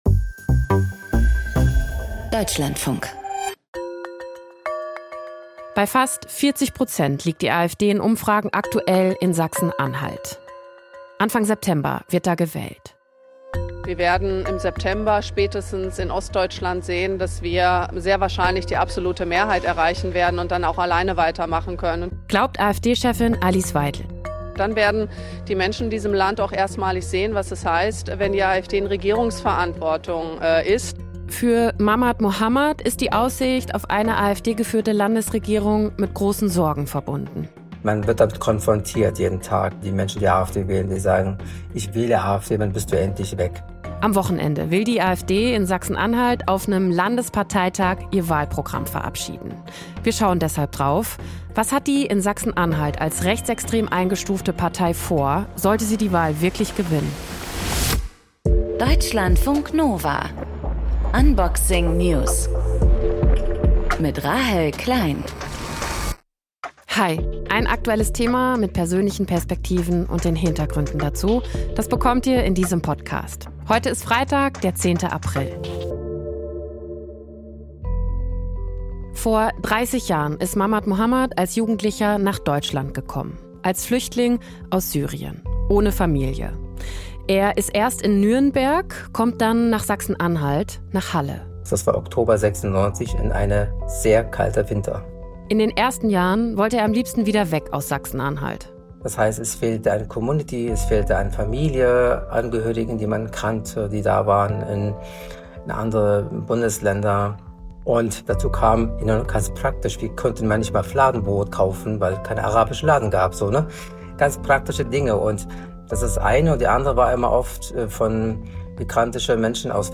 Moderation
Gesprächspartnerin